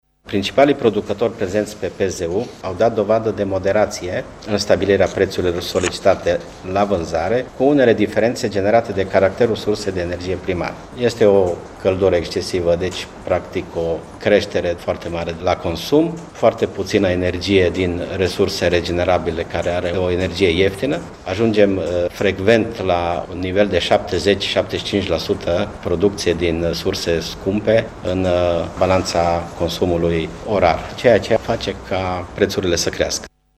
Şeful ANRE are şi o explicaţie pentru evoluţia preţului la energie în ultima perioadă: